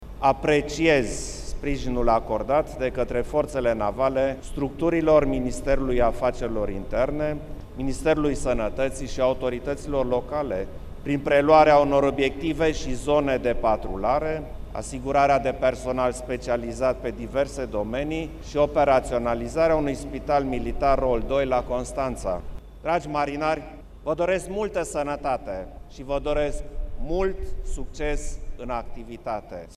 Este, iată, esențial să ne consolidăm capacitatea de anticipare și de reacție instituțională, să identificăm și să implementăm rapid soluții eficiente pentru a răspunde dificultăților pe care crize de o asemenea anvergură le pot genera în plan economic, dar și în plan social”, a transmis Klaus Iohannis în cadrul festivităților prilejuite de Ziua Marinei Române.
Președintele Klaus Iohannis a mers astăzi, pe litoral, la ceremonia dedicată Zilei Marinei.
Ceremonia dedicată Zilei Marinei a avut loc în aer liber.
15aug-11-Iohannis-la-Constanta.mp3